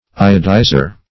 Iodizer \I"o*di`zer\, n.